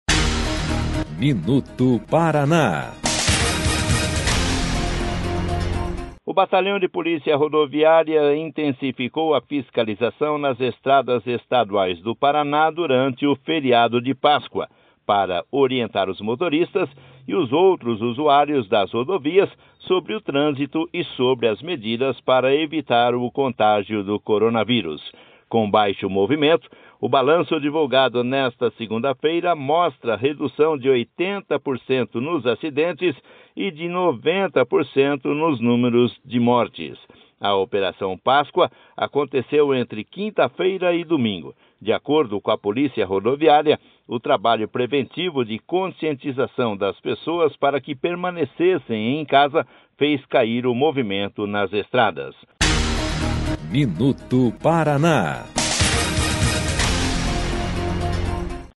MINUTO PARANÁ - BOLETIM DO BATALHÃO DA POLÍCIA RODOVIÁRIA NO FERIADO DE PÁSCOA